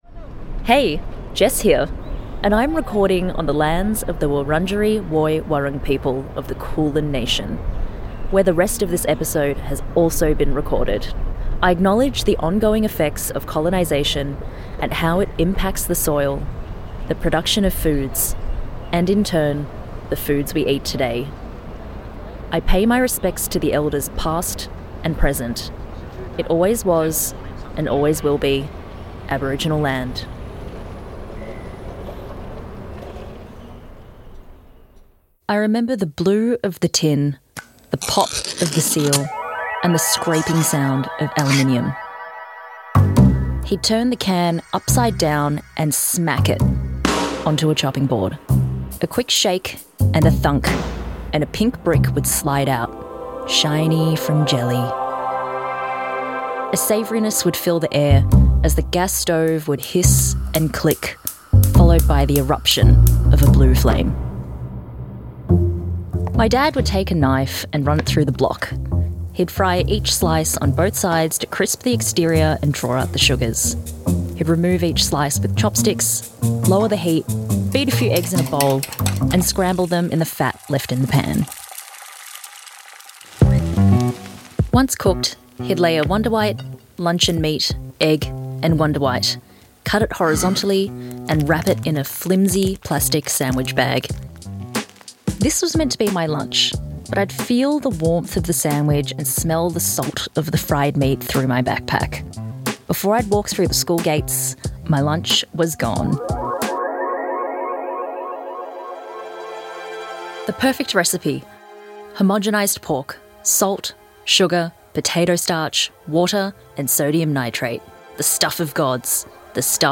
(soft city ambiance in the background)
(city sounds fade out)